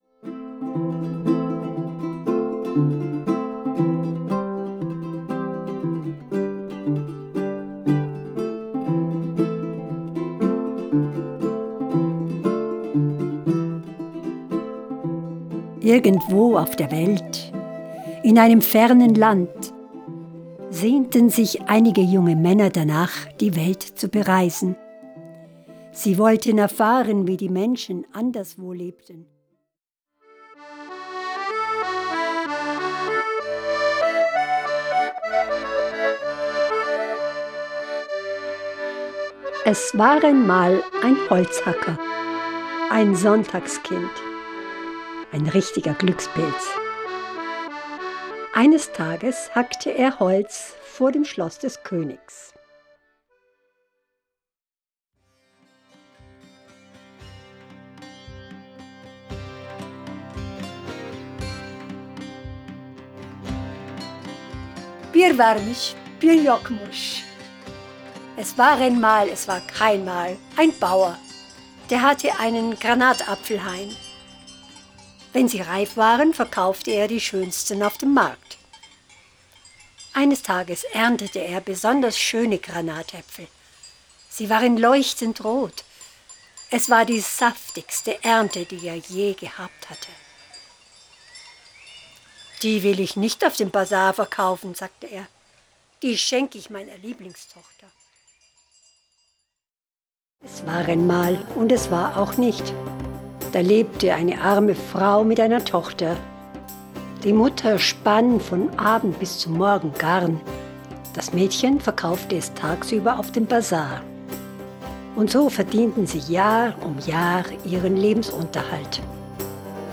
Wege ins Glück – HÖRBUCH